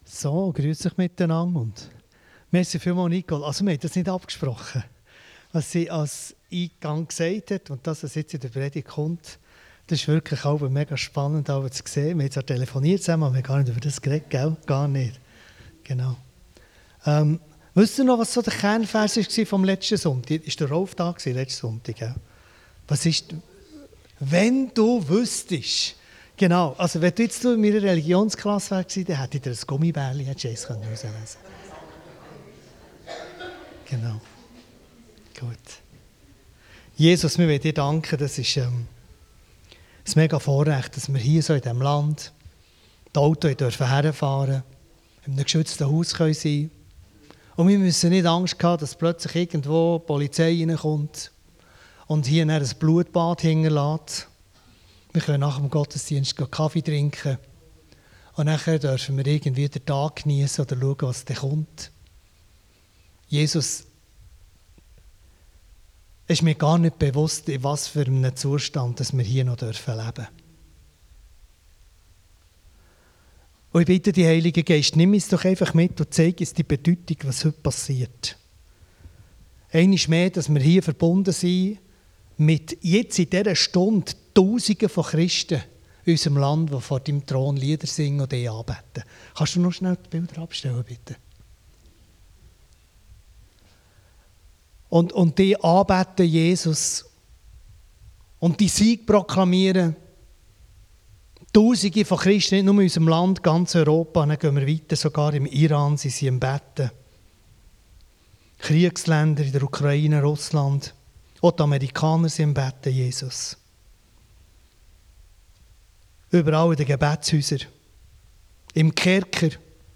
Mose 1,1 Dienstart: Gottesdienst Themen